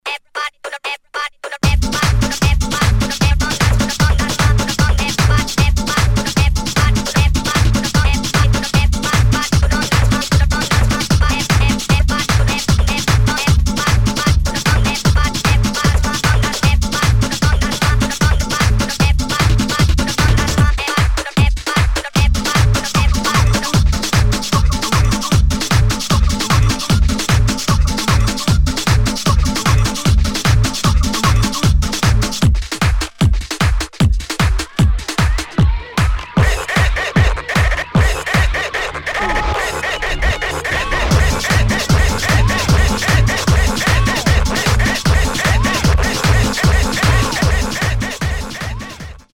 [ CUMBIA | ELECTRO ]